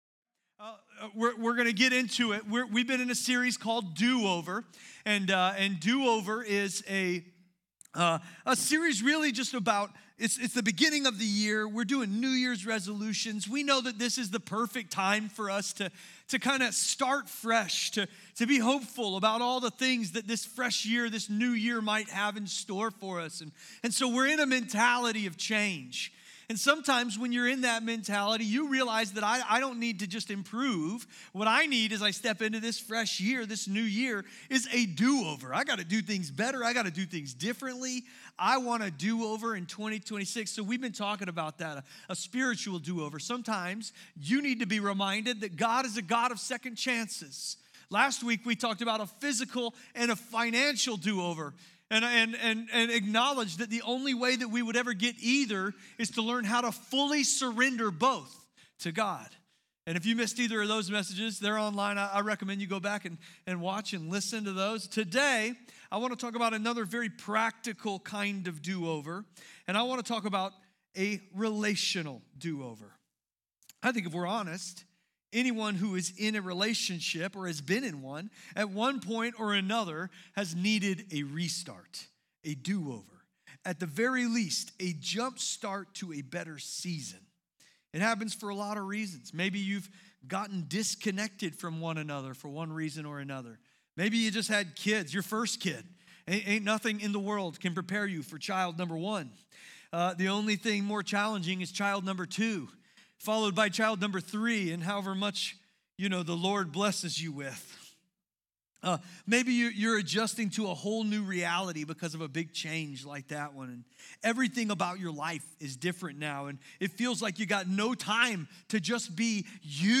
Do Over is a sermon series about God’s grace that meets us in our mistakes and offers a fresh start.